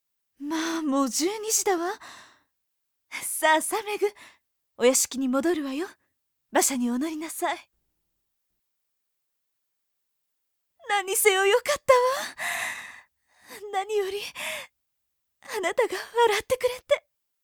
メインキャラクター・サブキャラクターの、イラスト＆簡単な紹介＆サンプルボイスです。